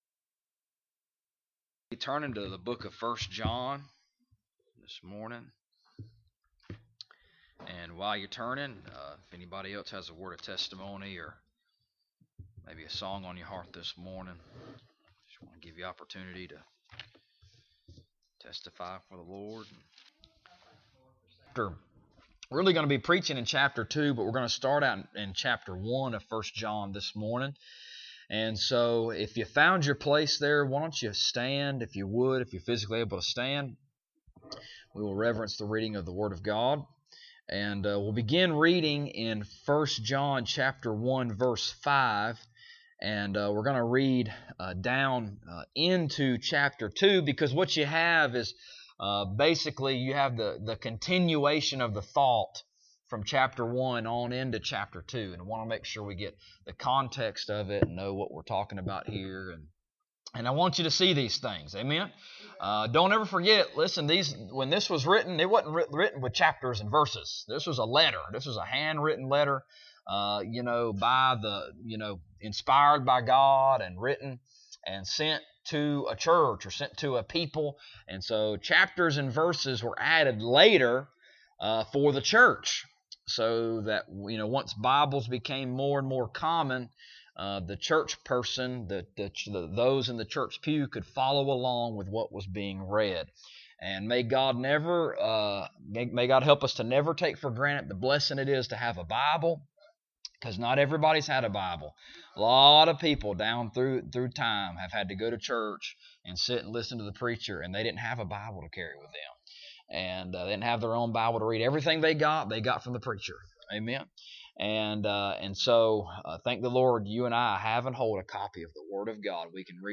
1 John Passage: 1 John 1:5 - 2:6 Service Type: Sunday Morning « Knowing to Know- The Details of the Message The Main Thing